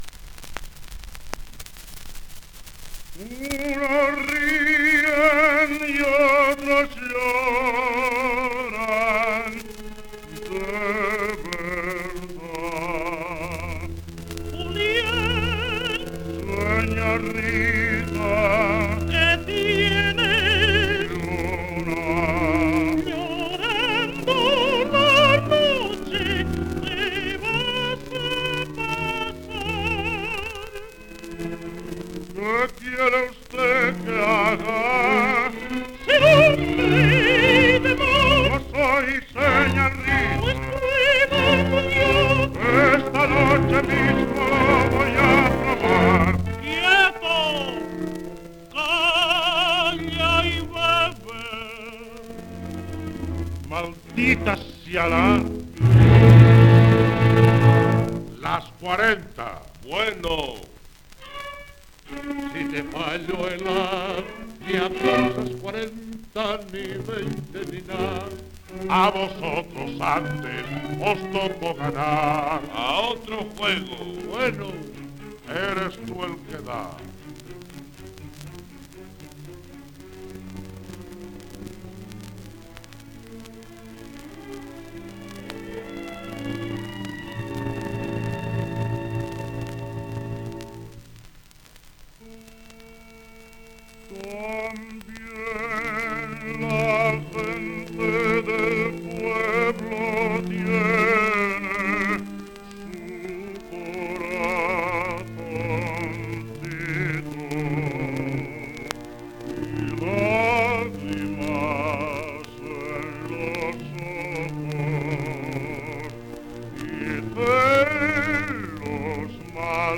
3 discos : 78 rpm, mono ; 25 cm.